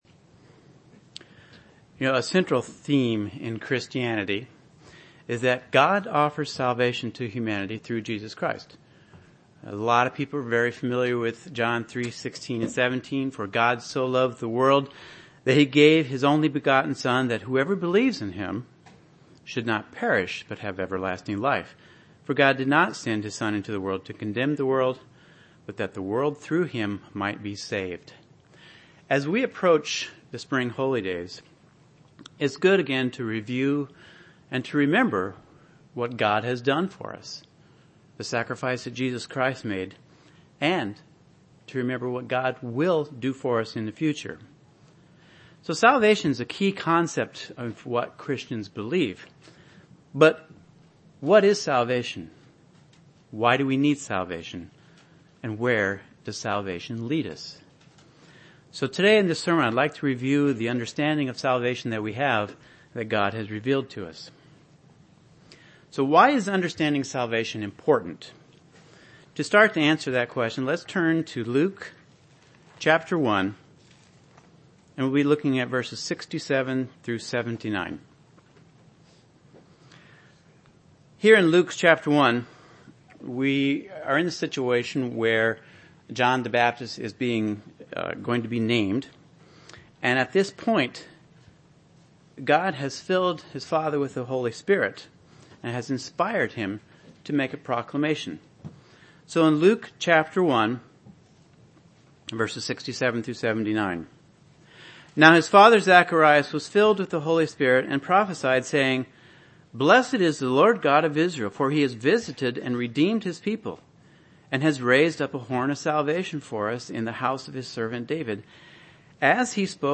Why do we need salvation and where does it lead us? This sermon is a review of the understanding of salvation that God has revealed to us.